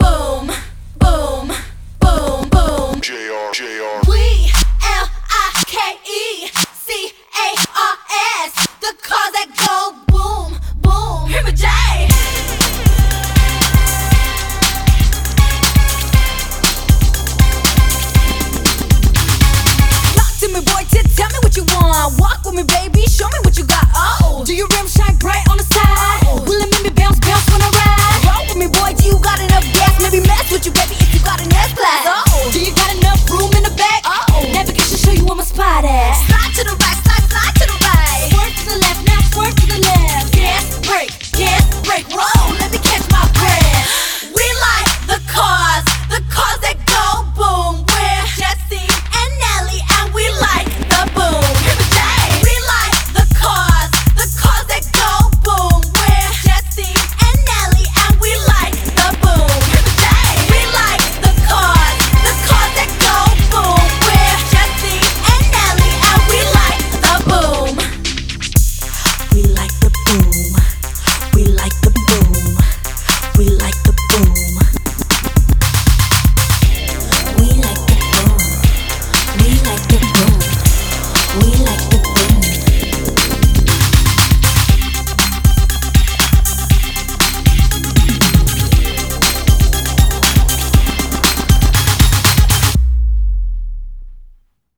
BPM119
Audio QualityPerfect (High Quality)
Genre: Pop Rap